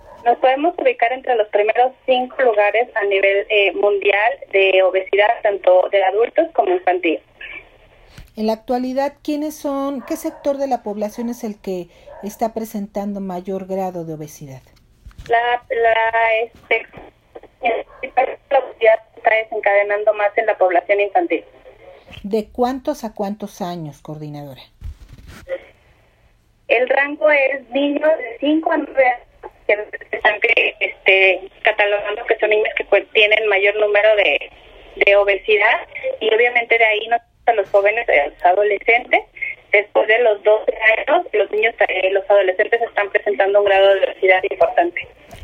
En entrevista con Efekto 10 Noticias, la especialista detalló con relación a los adultos mayores se están identificando dentro de un 40 un 50 por ciento de la población que cuenta con algún tipo de problemática.